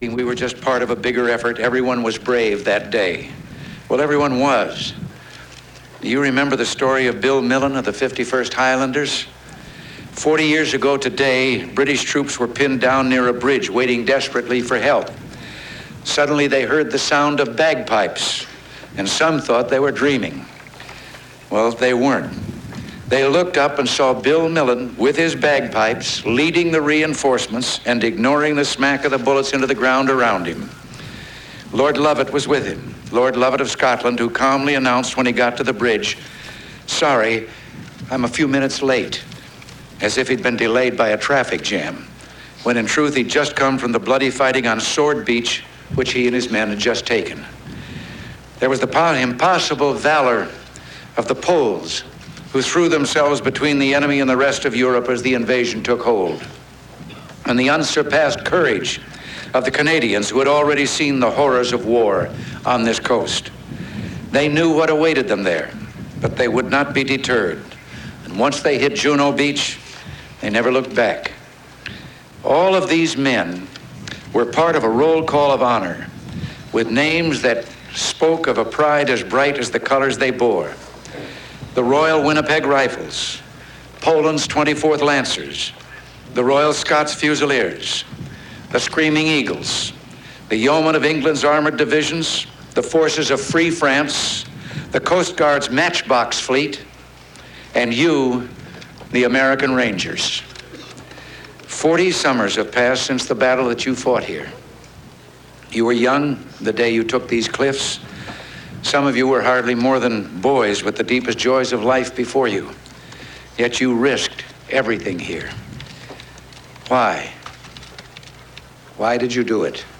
U.S. President Ronald Reagan delivers a speech at Normandy Beach head